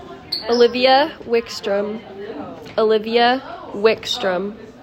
Name Pronunciation: